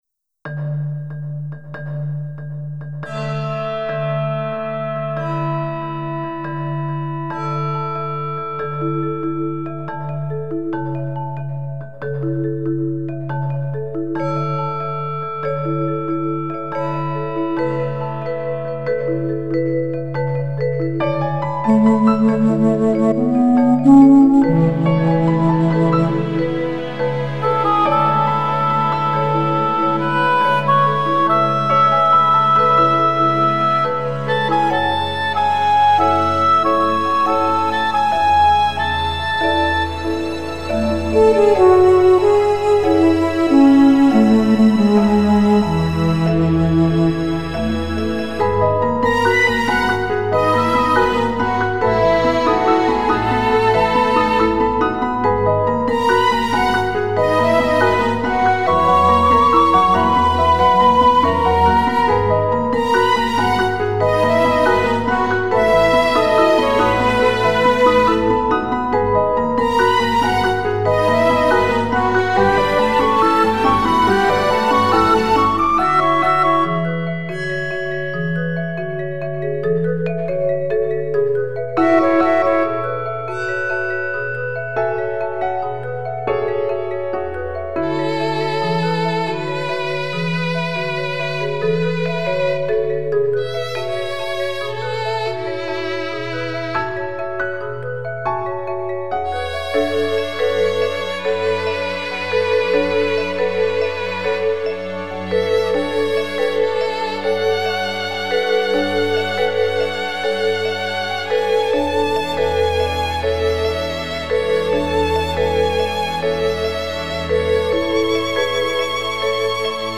(orchestral/minimalist)